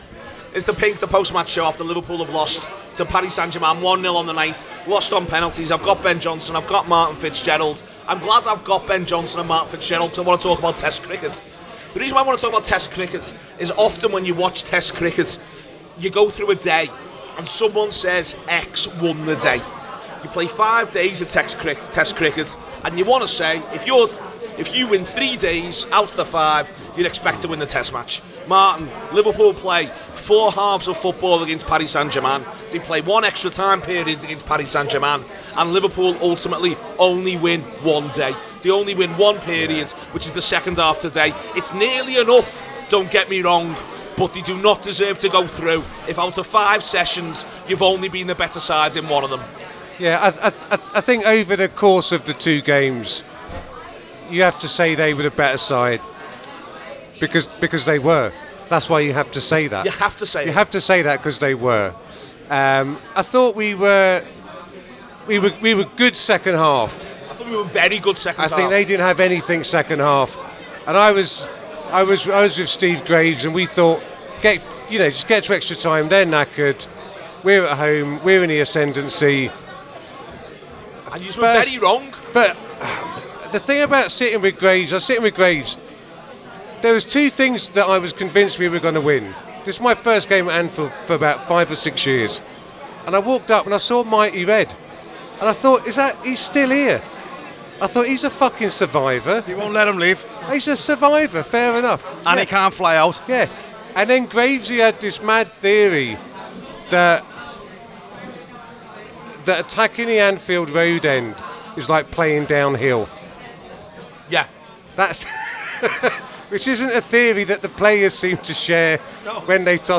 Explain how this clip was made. Below is a clip from the show – subscribe for more reaction to Liverpool exiting the Champions League…